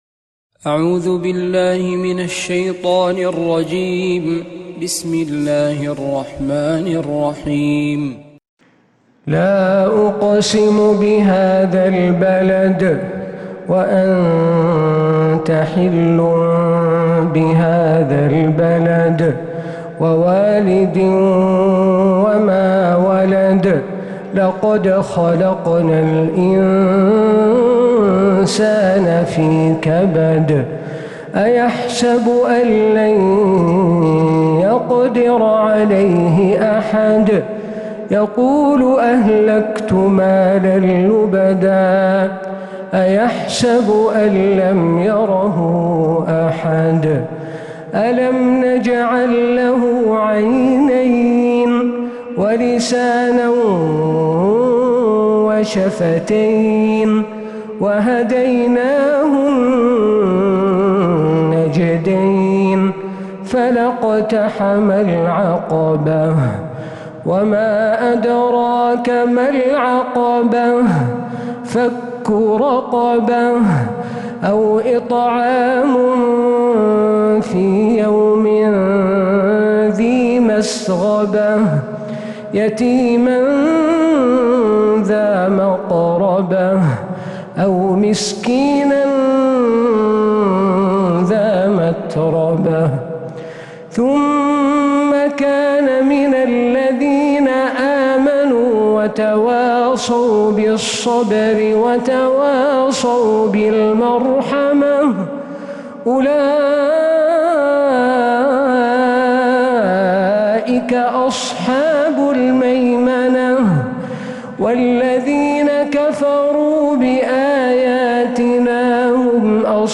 السور المكتملة 🕌